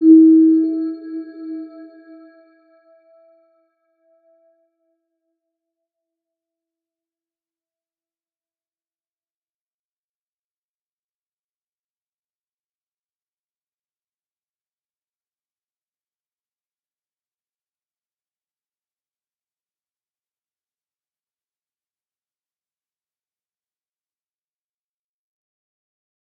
Round-Bell-E4-p.wav